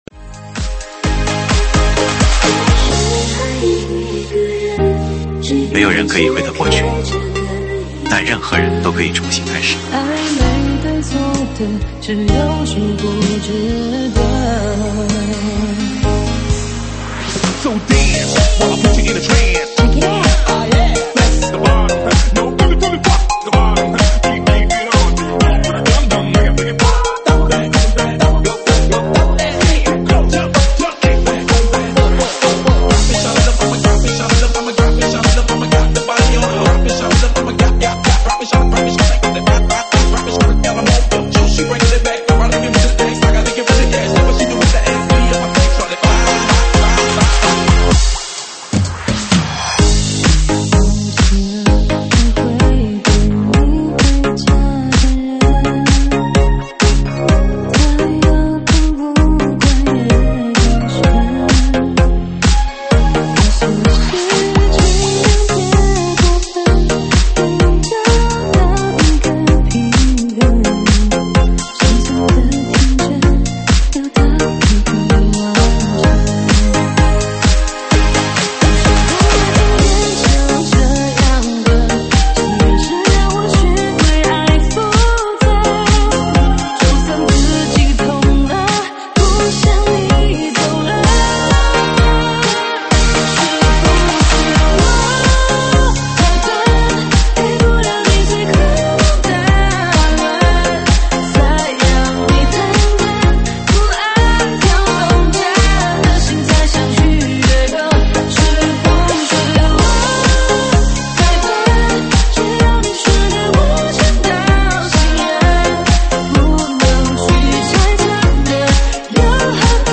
舞曲类别：现场串烧